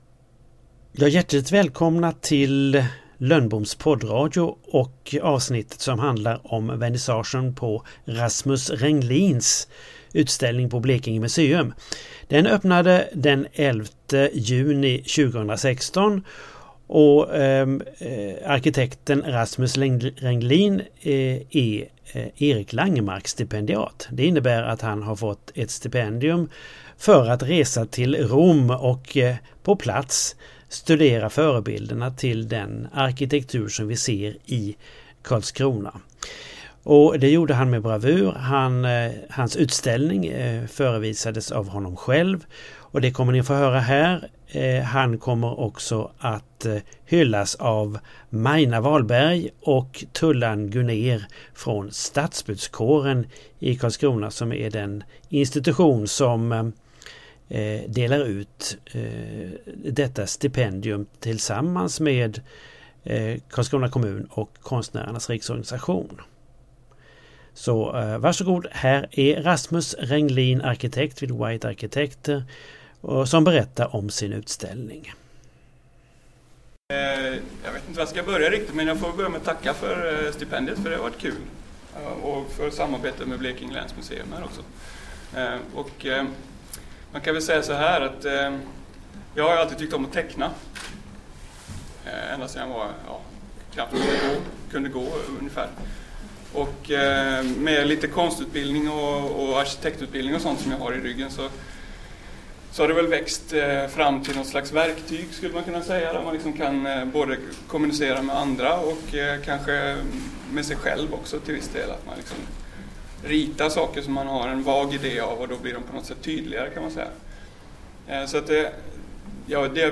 Här berättar han på Blekinge museum om den utställning som denna dag, 11 juni 2016, öppnades.